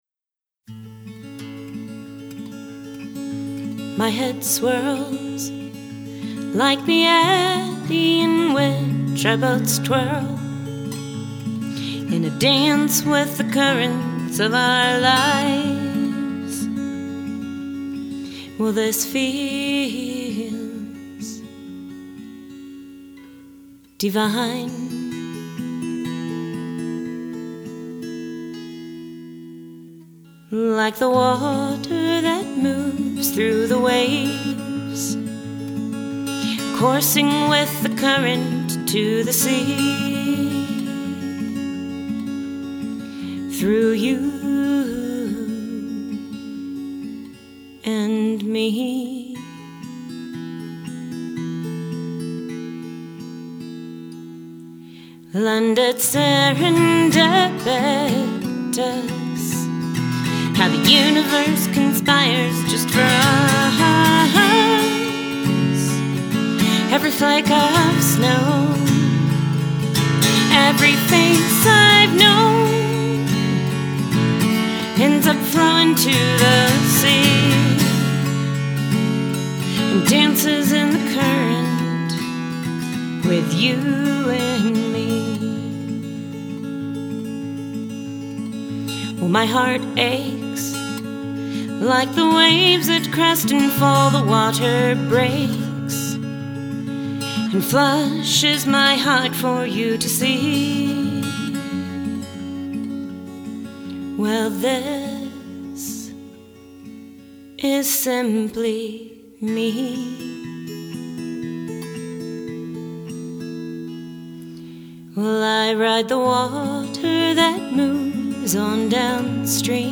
guitar & vocal